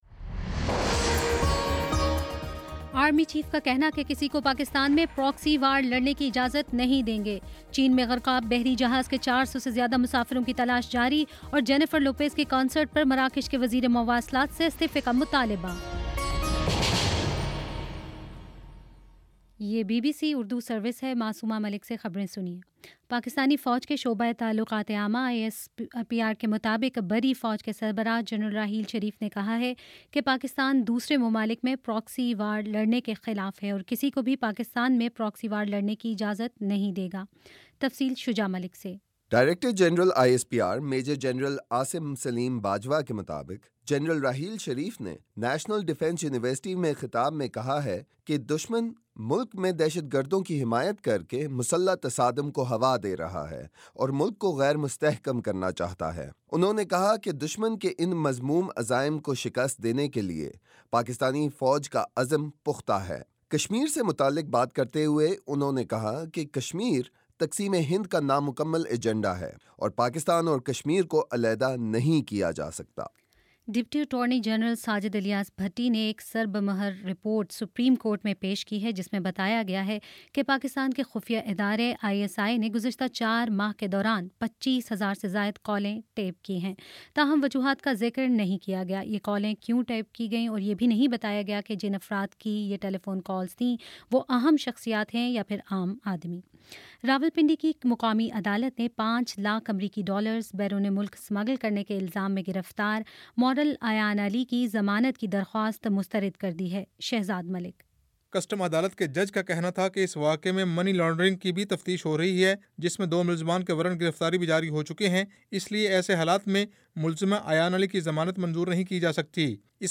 جون 3: شام سات بجے کا نیوز بُلیٹن